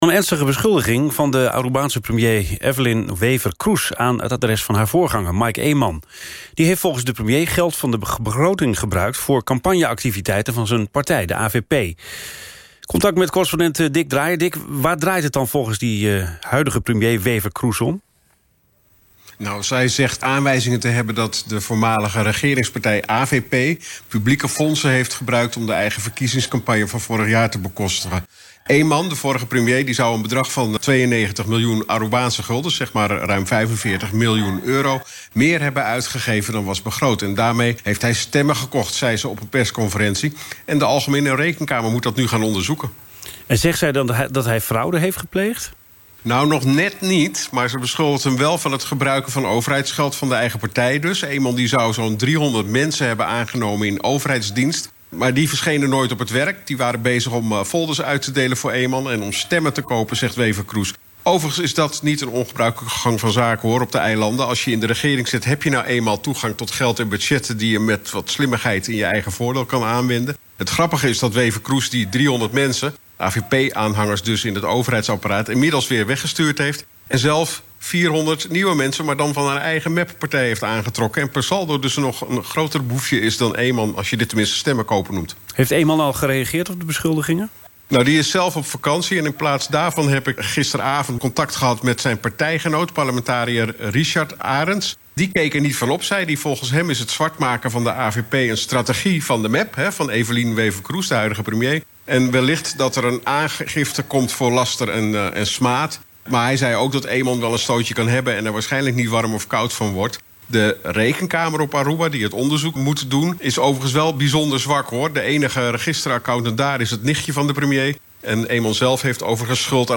kruisgesprek op NPO radio 1 en de reactie daarop van Rocco Tjon, fractievoorzitter van regeringspartij MEP.